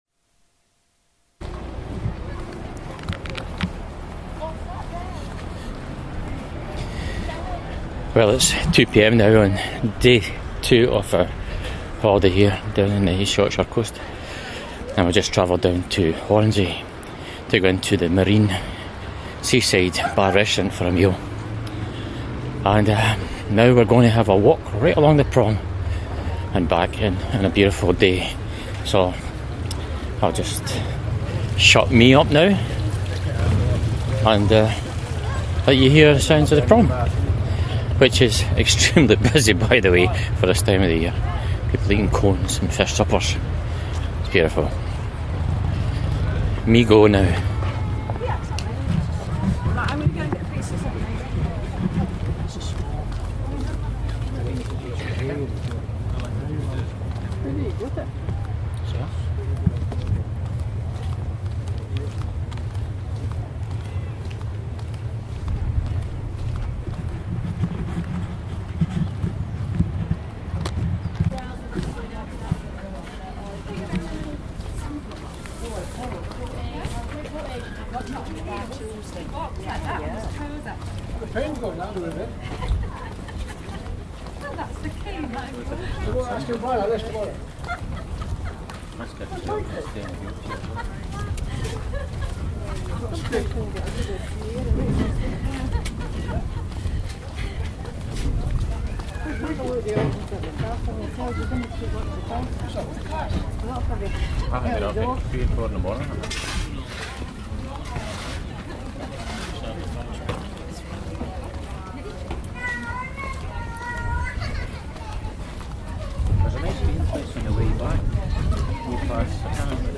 Barmston Holiday - Day2 pt3 - Hornsea Promenade soundscape.